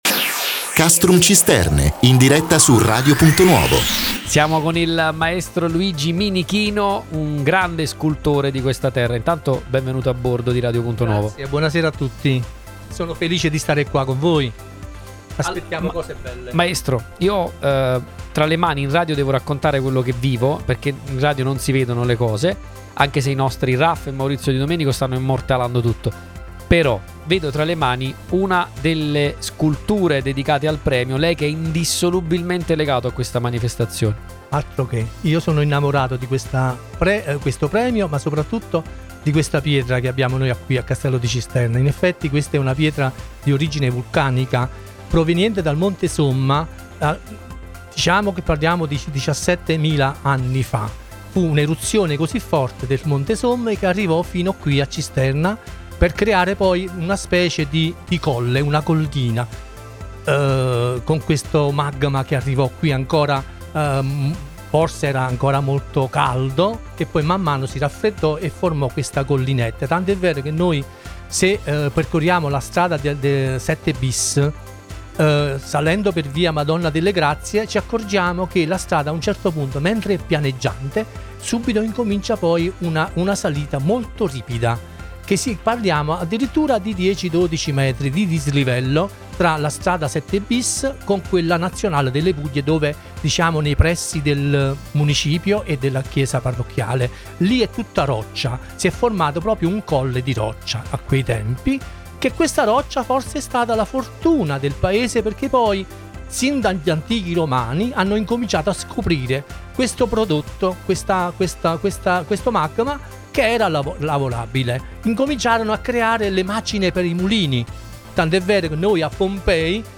Il Premio Letterario Castrum Cisternae ha ospitato ai microfoni di Radio Punto Nuovo un testimone d’eccezione della storia locale